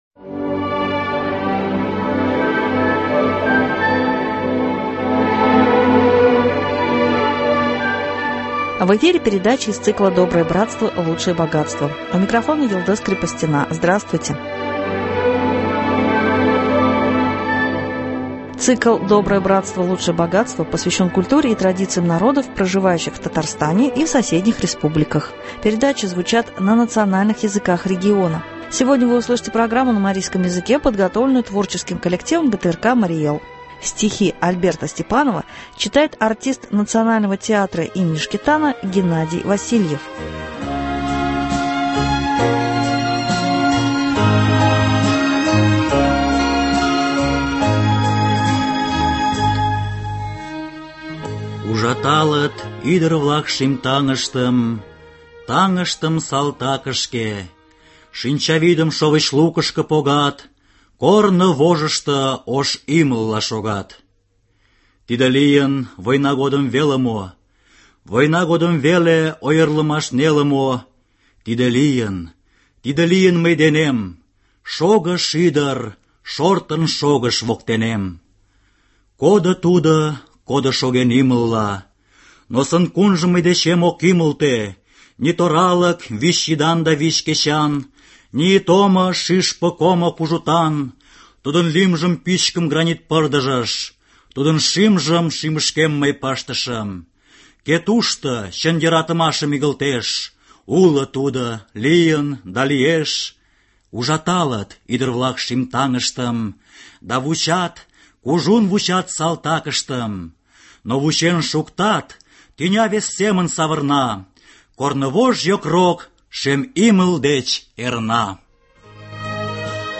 Стихи Альберта Степанова читает артист